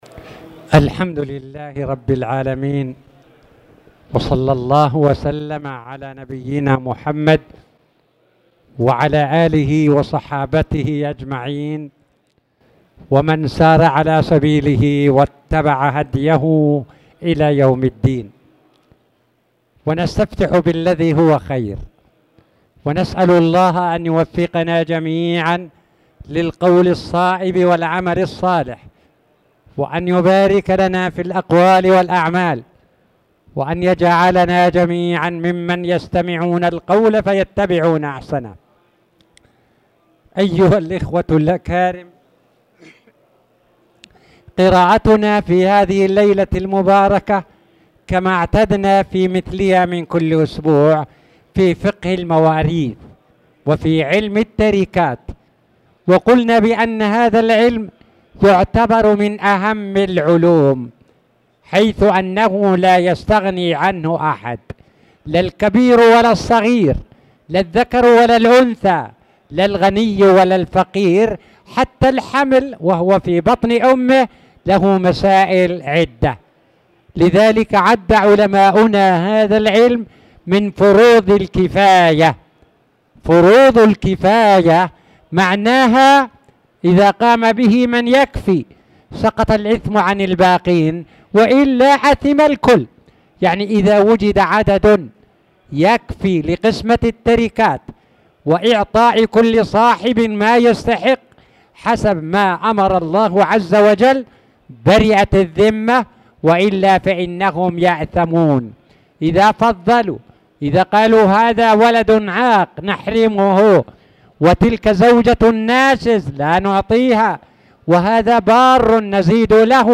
تاريخ النشر ١٩ ذو القعدة ١٤٣٧ هـ المكان: المسجد الحرام الشيخ